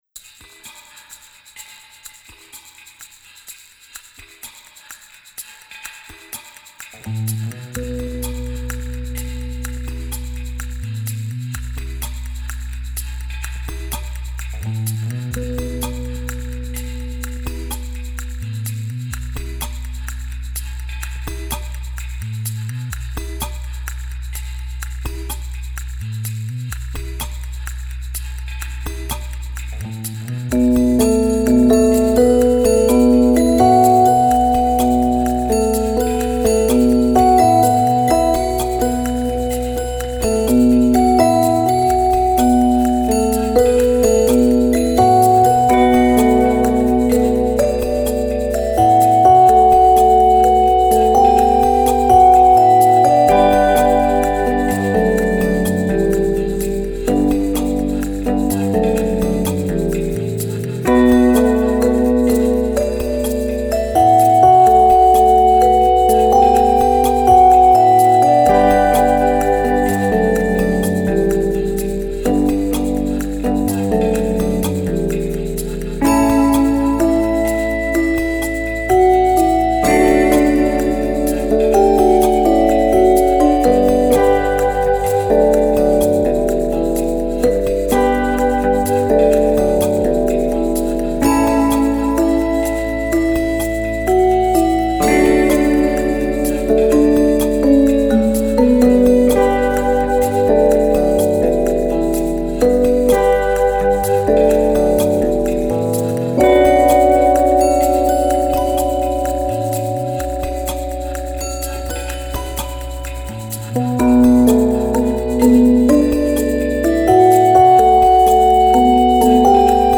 Genre: Instrumental, Music for Children